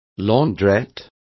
Complete with pronunciation of the translation of launderette.